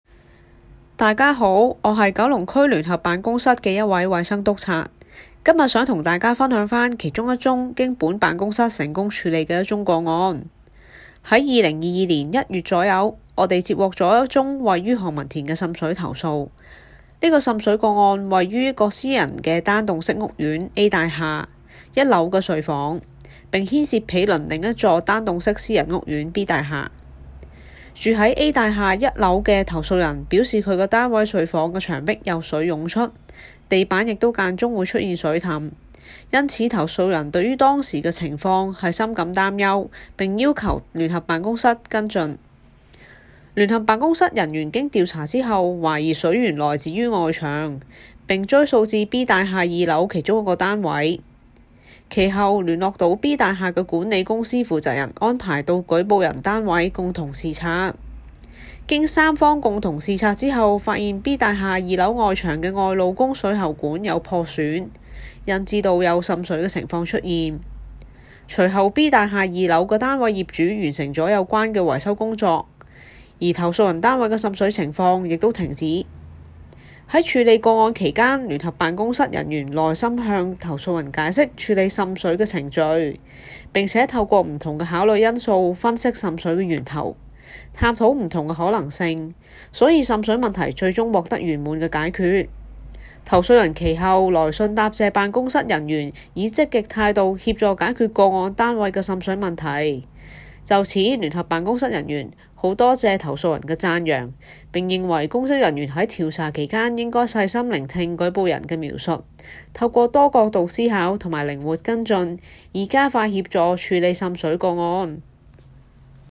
Case No. Year (Region) The voice of Joint Office investigating officer